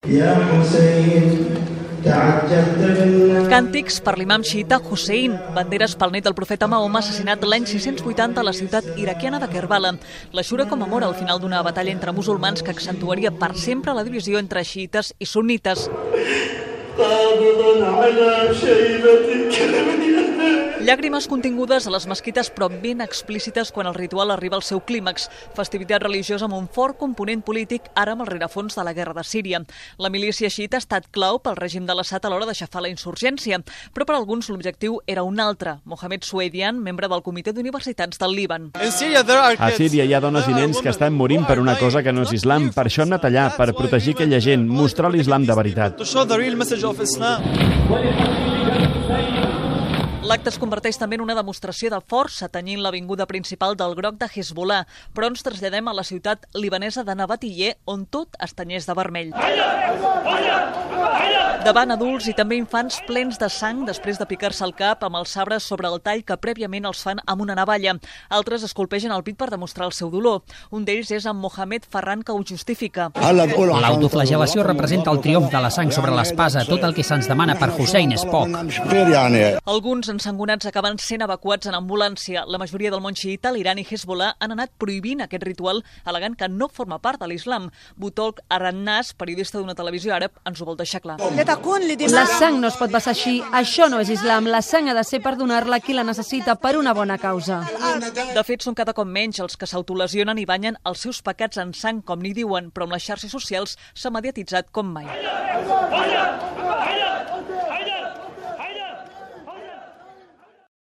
Crònica des del Líban. La comunitat islàmica celebra avui l'Ashura, una festivitat especialment important per als xïïtes.
Informatiu